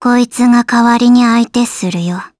Gremory-Vox_Skill6_jp.wav